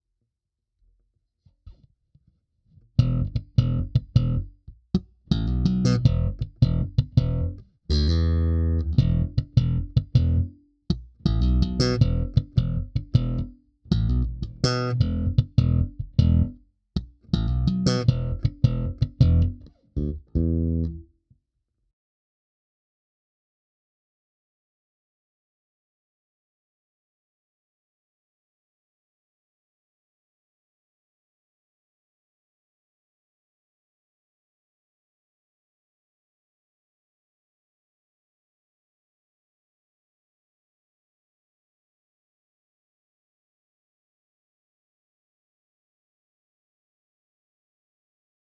Naskytla se mi příležitost přímého porovnání na mém setupu. Jedná se o 55-02 vs. Stingray Special 4H, stejné stáří strun, hmatník obojí palisandr.
Lakland 55-02 kobylka HB slap